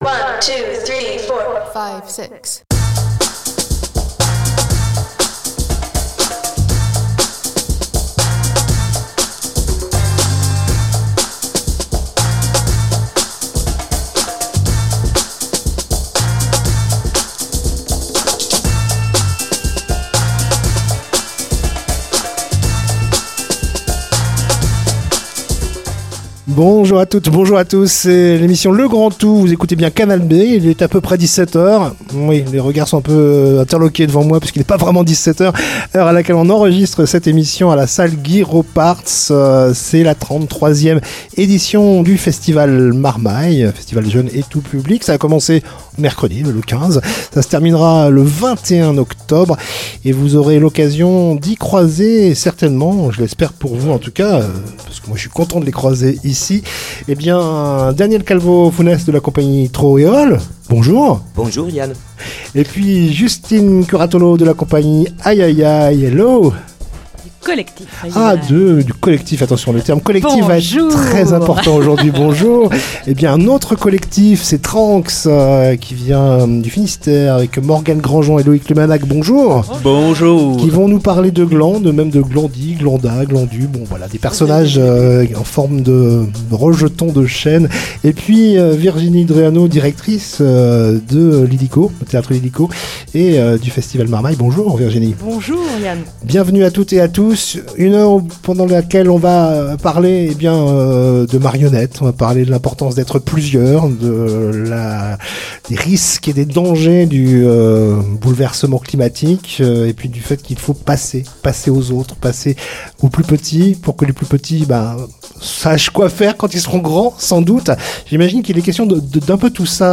itvs 16/10/2025 60 mn Émission spéciale Festival Marmaille 2025 enregistrée à la salle Guy Ropartz dans laquelle nous parlons de spectacles à découvrir dès le plus jeune âge !